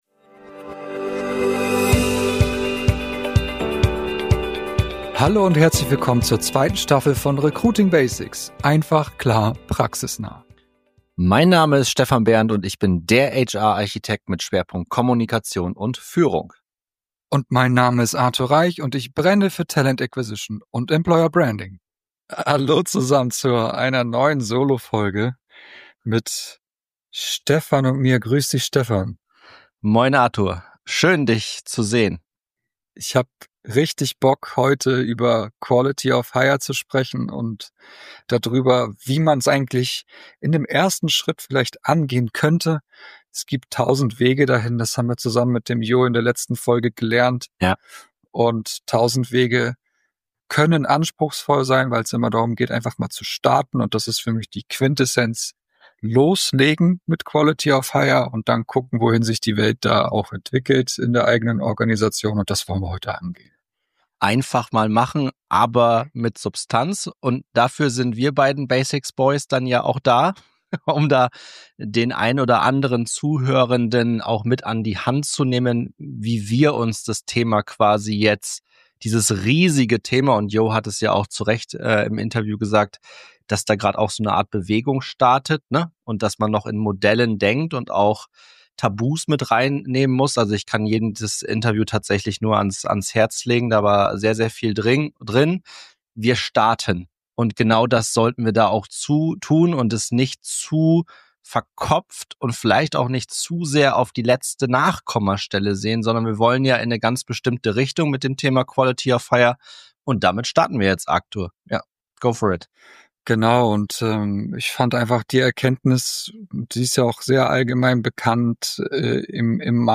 In dieser Solo-Folge